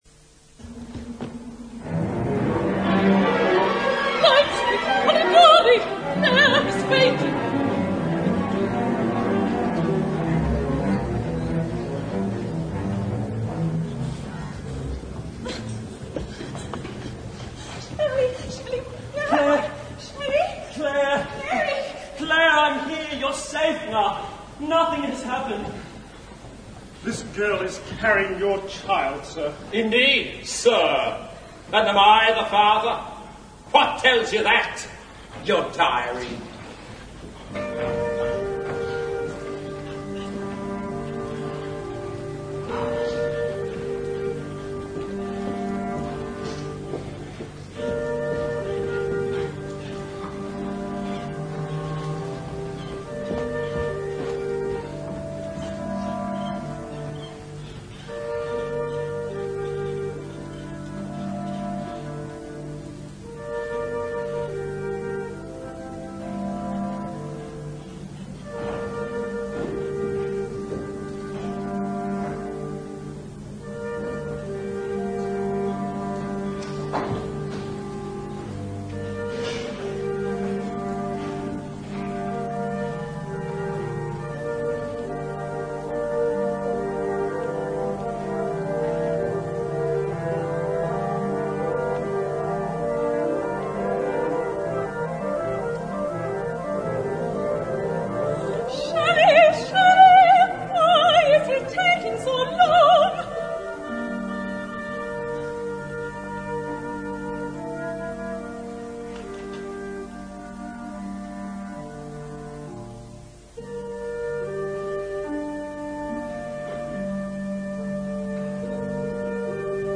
Ópera en dos actos